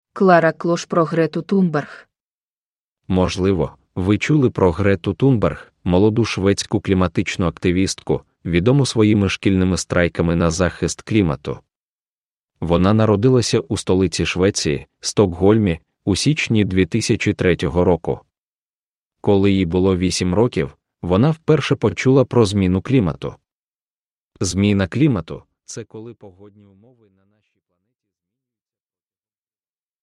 Greta Thunberg – Ljudbok – Laddas ner
Uppläsare: Reedz Audiobooks